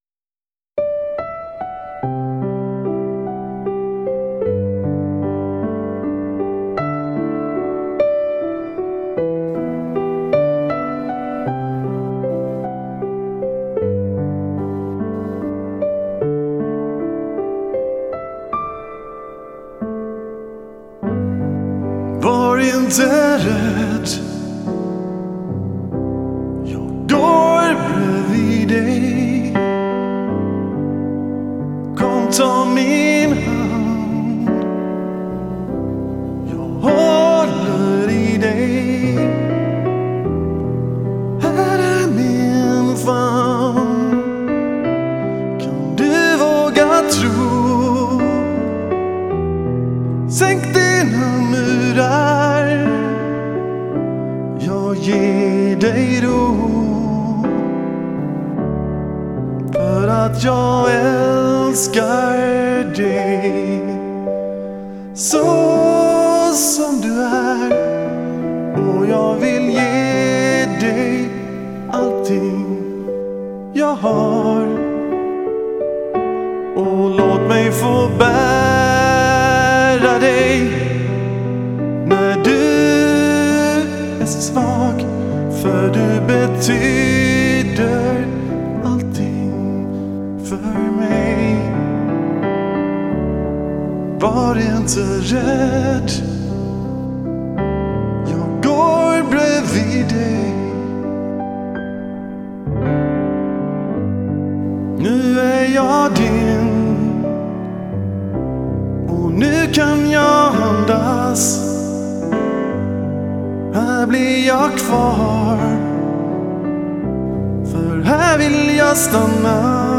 Fantastisk fin kärlekslåt som jag tillägnar min pappa.